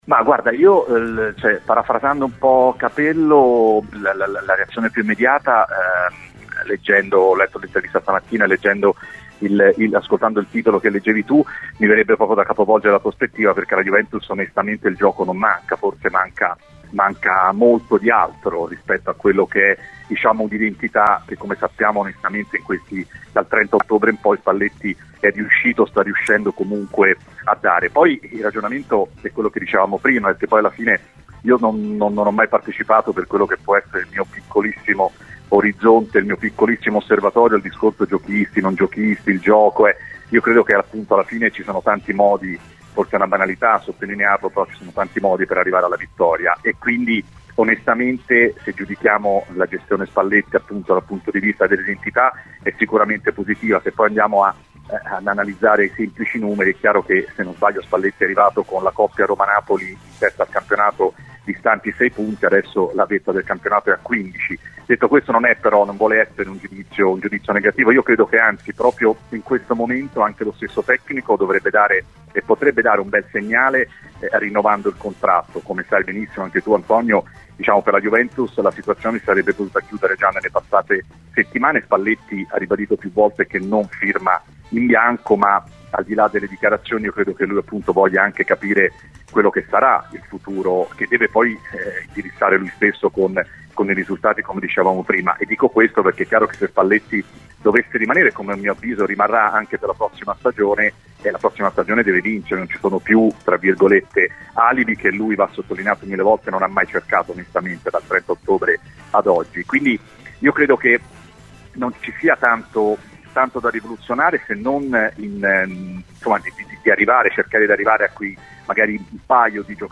Ospite a “Cose di Calcio”